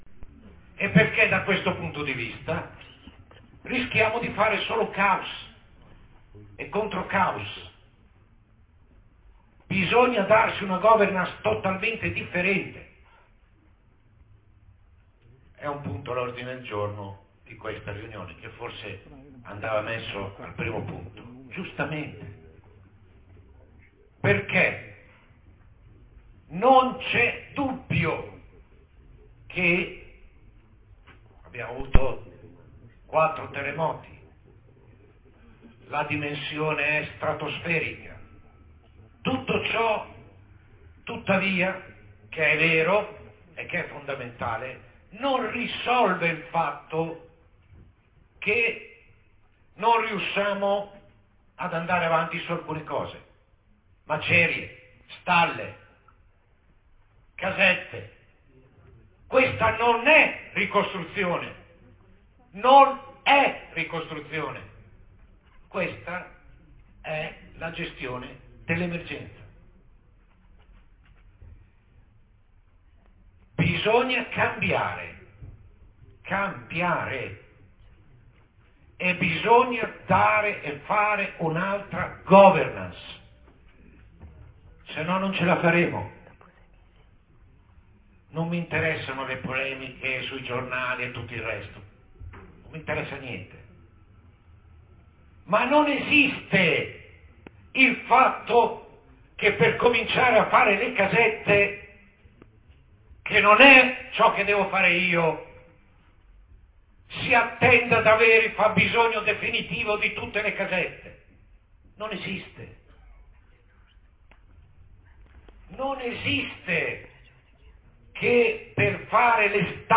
L’AUDIO INTEGRALE REGISTRATO DA PANORAMA